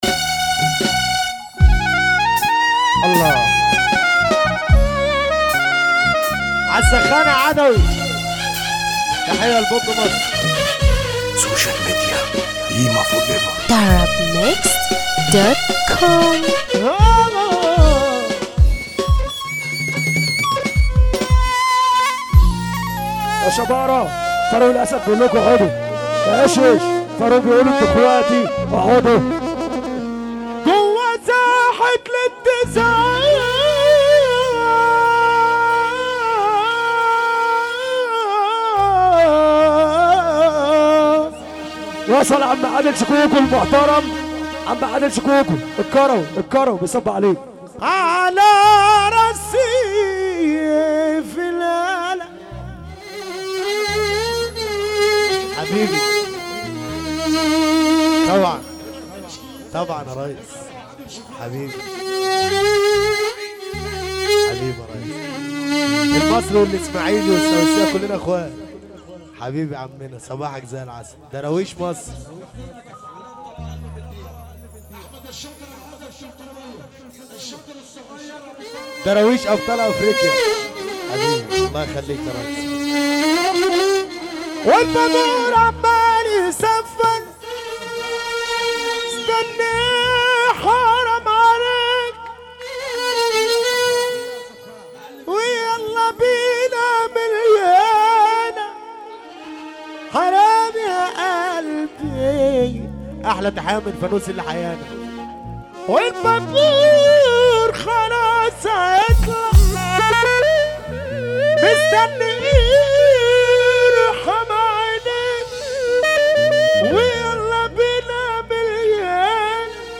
موال
حزين جدا